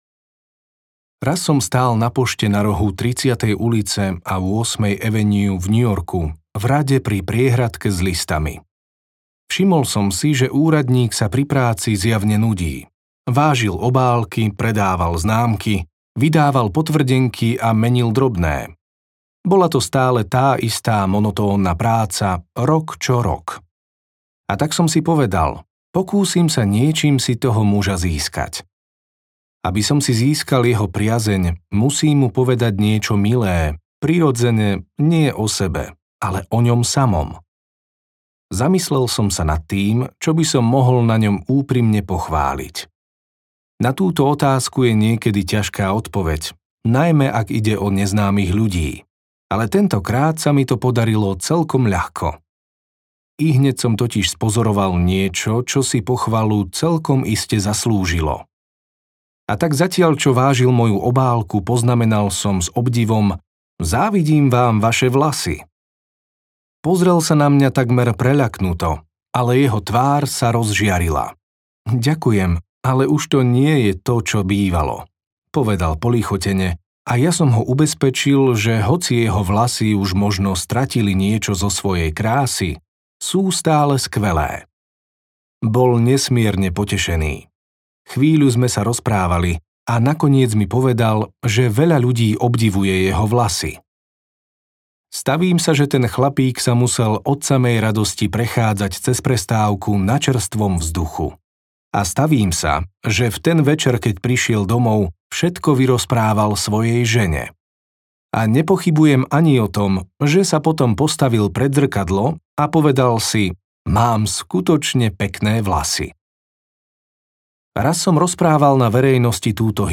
Ako si získavať priateľov a pôsobiť na ľudí audiokniha
Ukázka z knihy